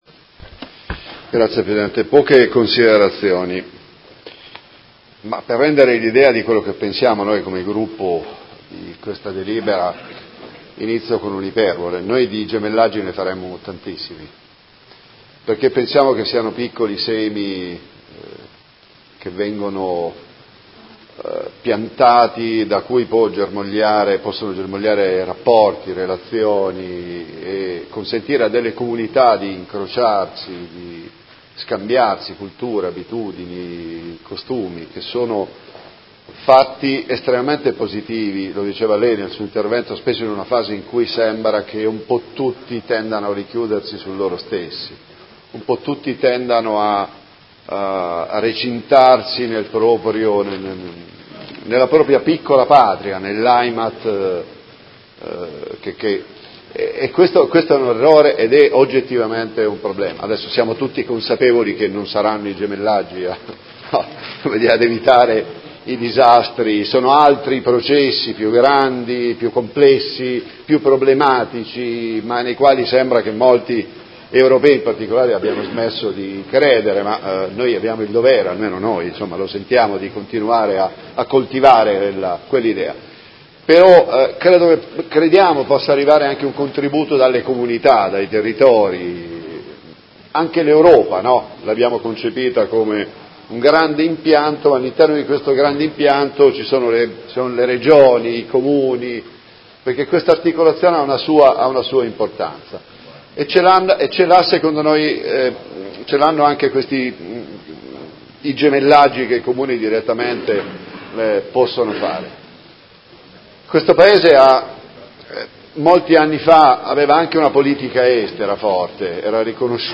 Seduta del 17/01/2019 Dibattito.